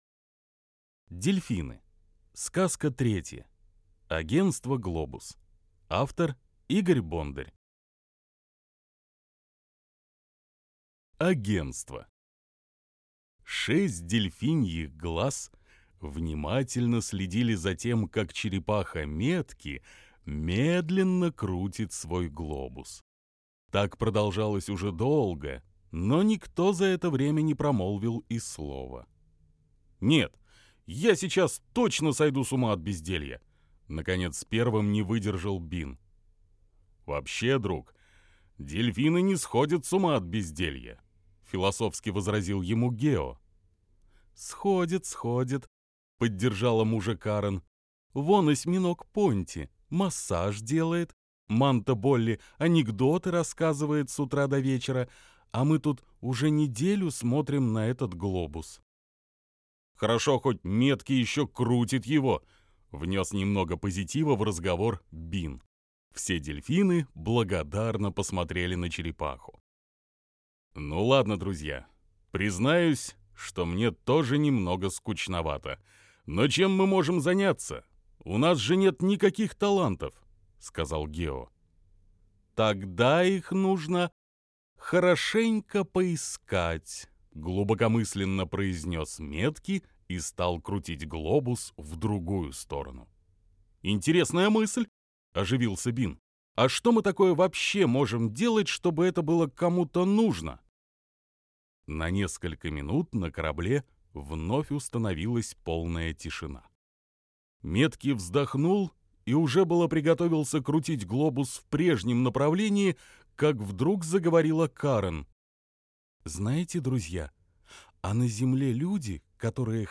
Дельфины. Агентство "Глобус" - аудиосказка Бондаря - слушать